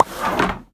Minecraft Version Minecraft Version snapshot Latest Release | Latest Snapshot snapshot / assets / minecraft / sounds / ui / loom / take_result1.ogg Compare With Compare With Latest Release | Latest Snapshot